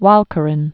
(wälkə-rən, wôlə-)